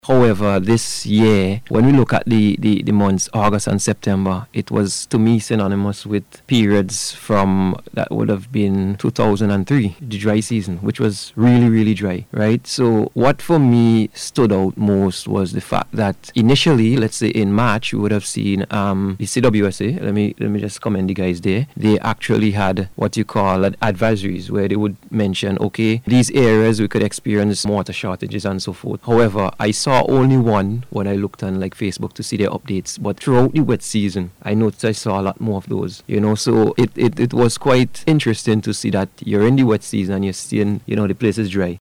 Speaking on NBC Radio this morning